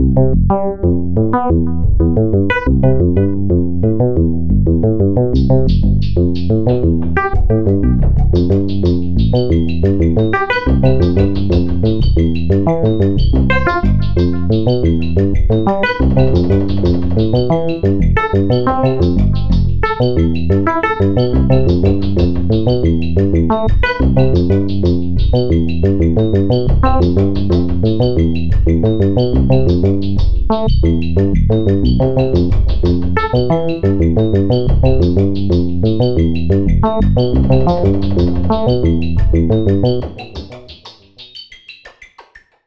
Pieza Intelligent dance music (IDM)
melodía
repetitivo
sintetizador
Sonidos: Música